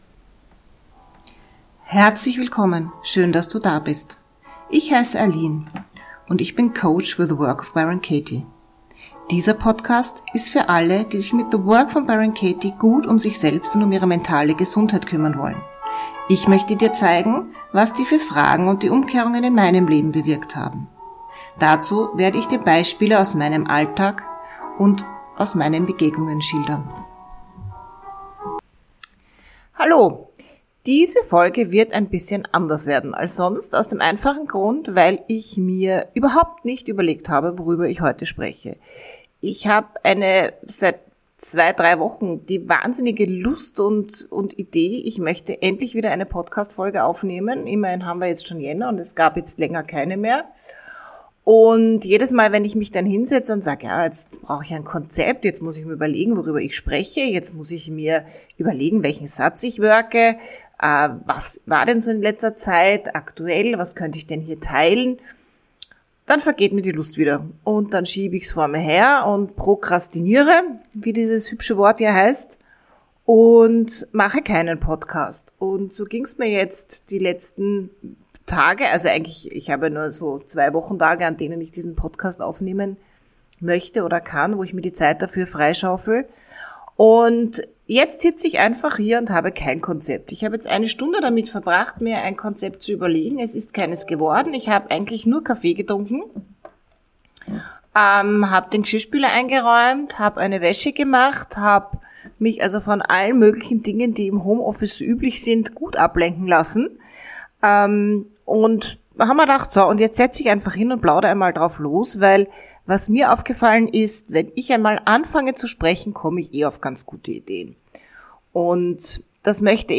kein Plan, was ich sagen will und trotzdem das Mikrophon laufen gelassen, DAS kam dabei raus.
...einfach mal drauflosgeredet, ganz ohne Vorbereitung!